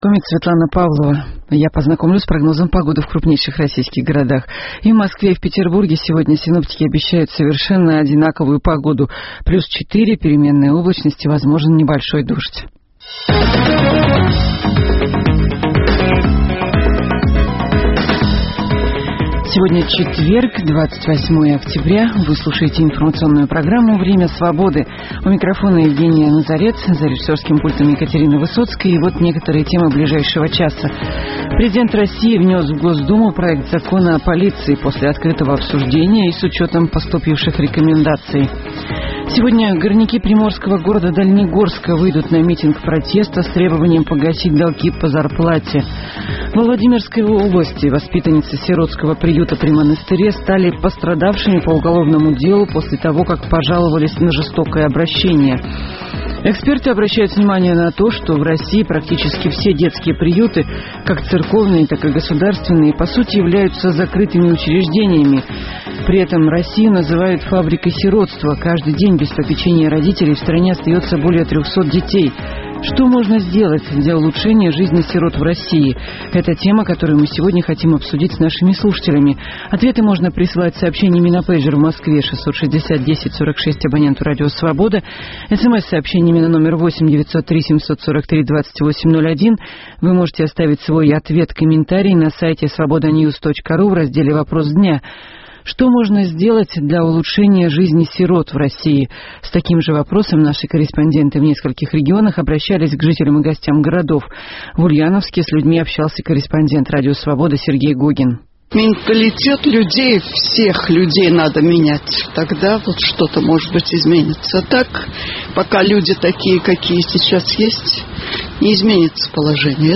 Перспективы и подробности первых событий дня наступившего, дискуссии с экспертами на актуальные темы, обсуждение вопроса дня со слушателями в прямом эфире.